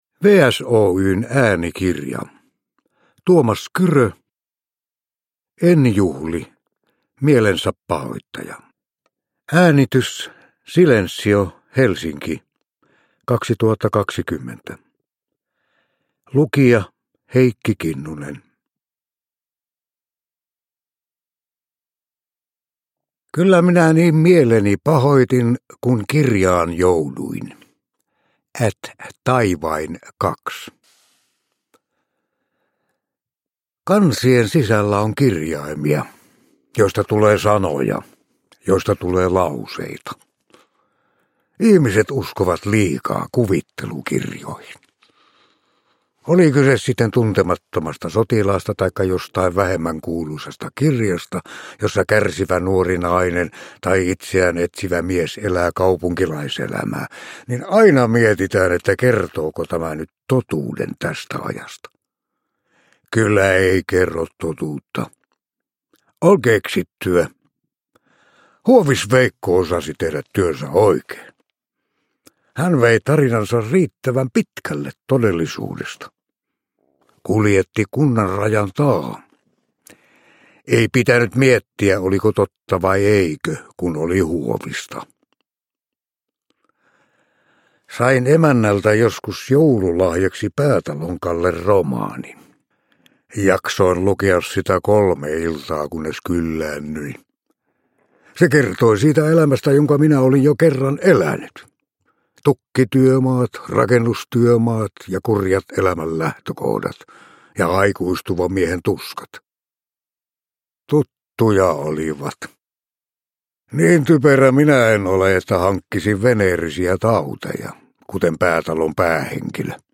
En juhli, Mielensäpahoittaja – Ljudbok
Uppläsare: Heikki Kinnunen